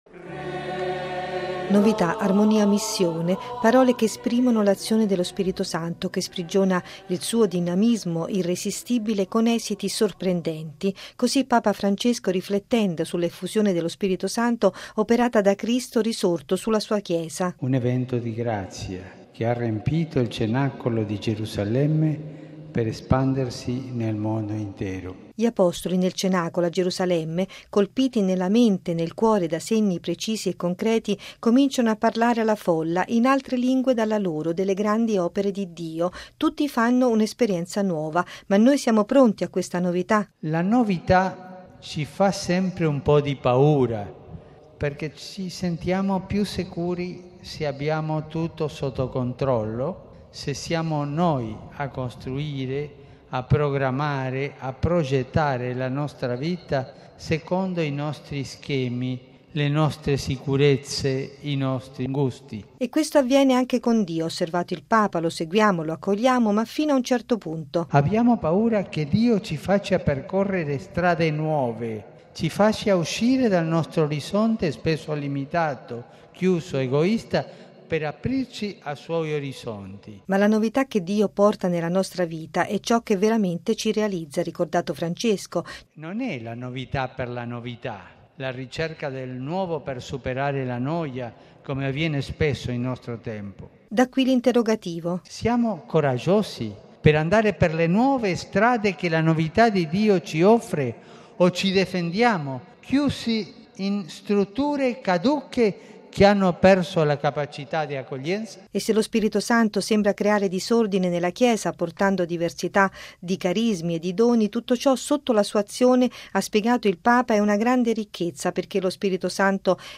Così Papa Francesco nell’omelia della Messa di Pentecoste, celebrata questa mattina in una piazza San Pietro gremita dai pellegrini di movimenti, nuove comunità, associazioni, aggregazioni laicali di tutto il mondo, giunti a Roma in occasione dell’Anno della Fede. 70 tra cardinali e vescovi e 400 sacerdoti hanno concelebrato la liturgia.